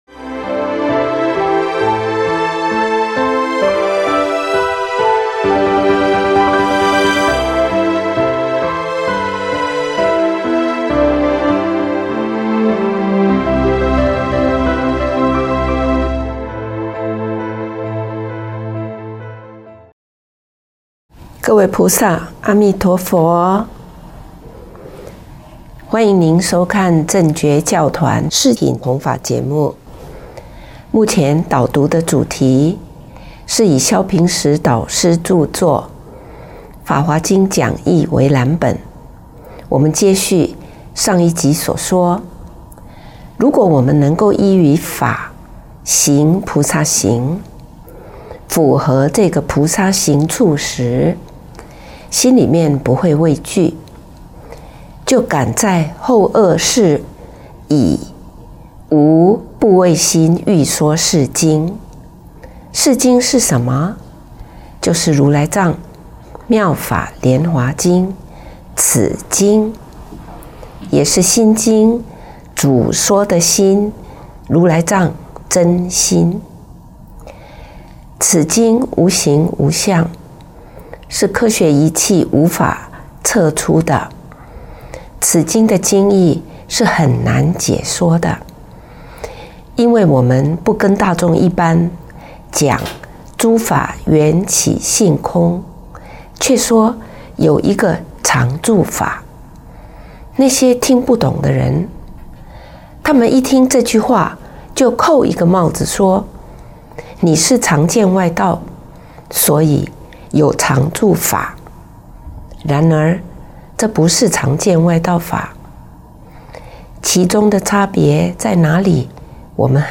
三乘菩提系列讲座，正觉同修会影音，同修会音频，同修会视频